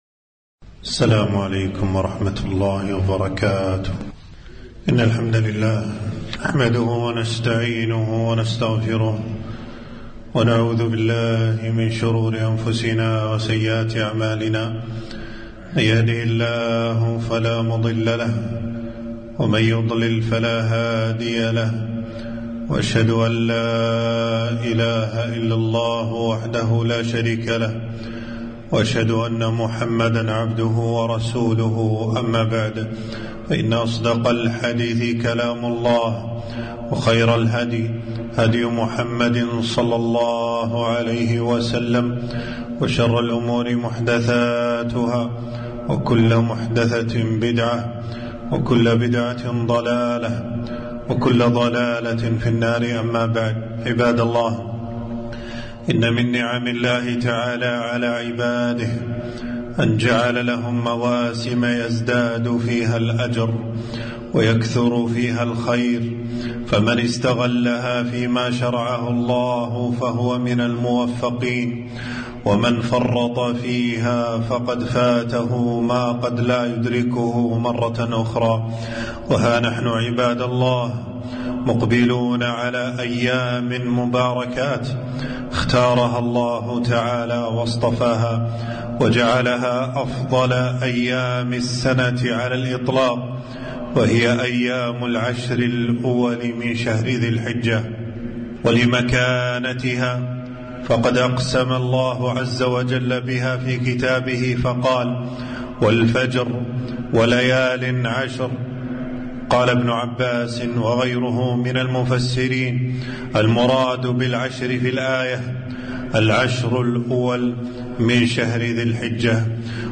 خطبة - اغتنام العشر الأول من ذي الحجة